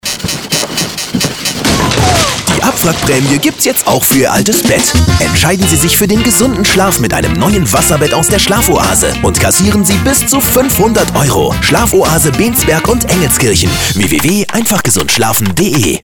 Radiowerbung Wasserbett :